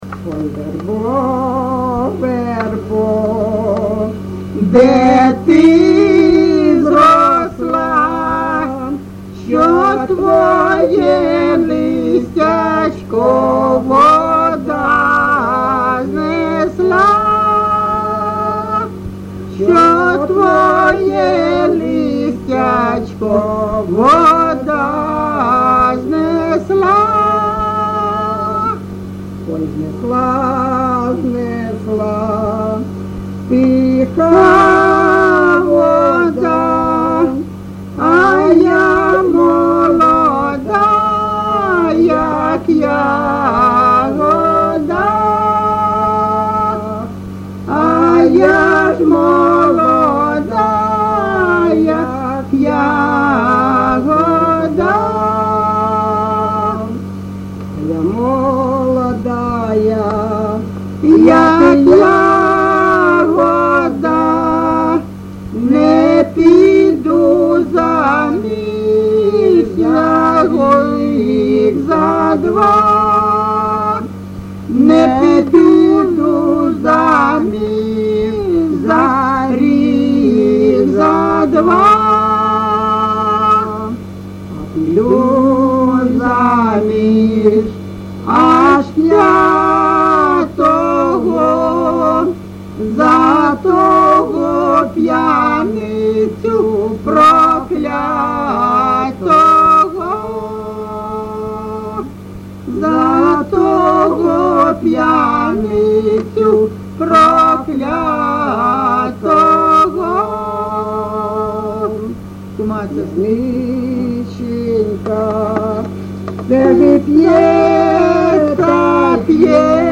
ЖанрПісні з особистого та родинного життя
Місце записус. Калинове Костянтинівський (Краматорський) район, Донецька обл., Україна, Слобожанщина